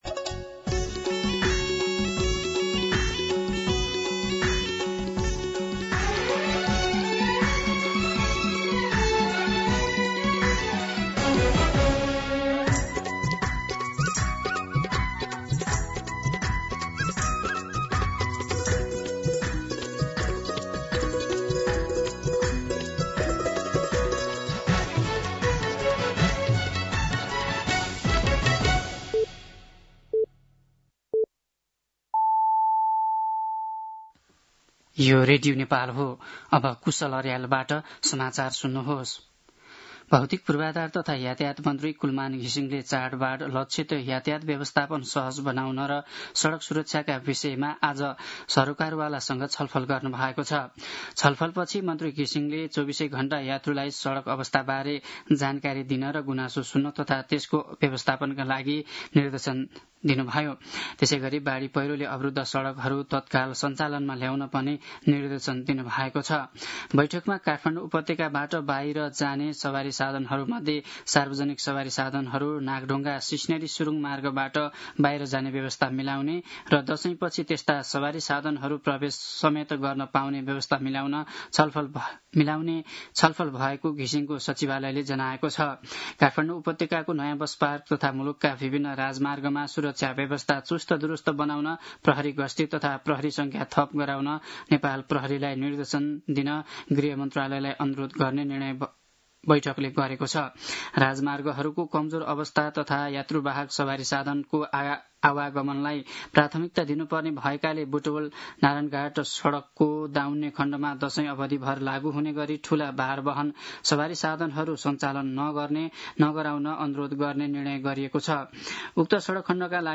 दिउँसो ४ बजेको नेपाली समाचार : ४ असोज , २०८२
4-pm-Nepali-News-3.mp3